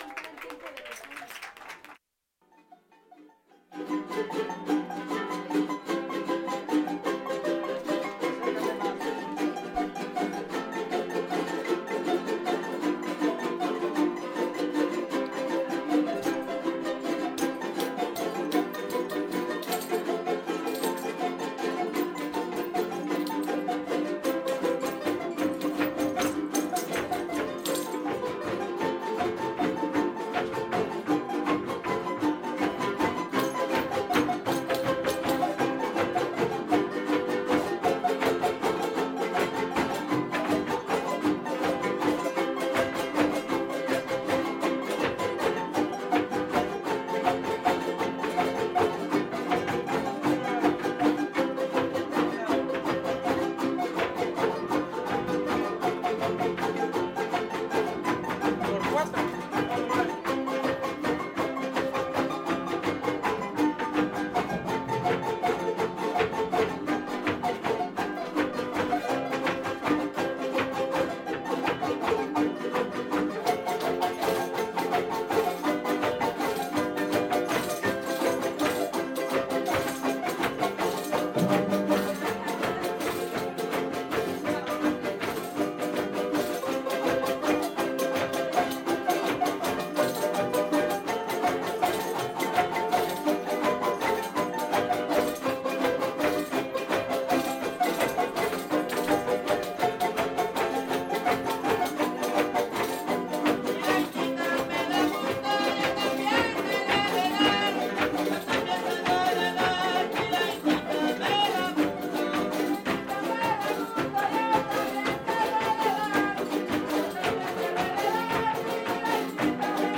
Fiesta Patronal de San Antonio de Padua